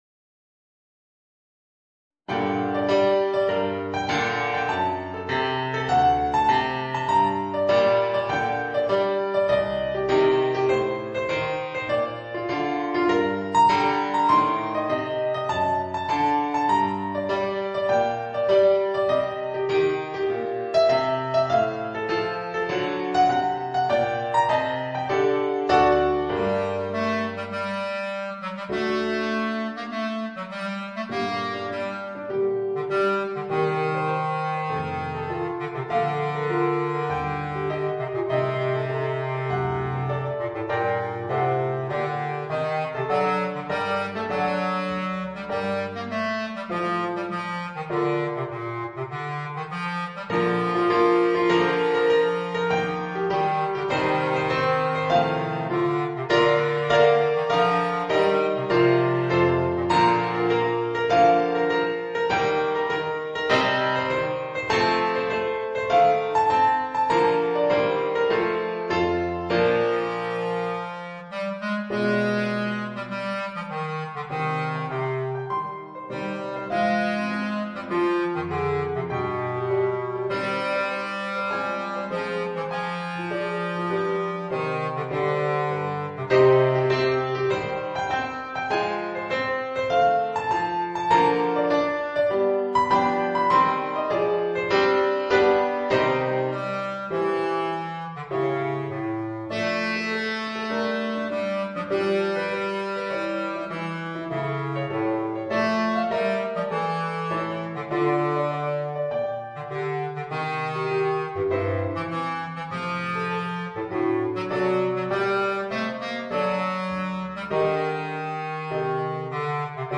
Voicing: Bass Clarinet and Organ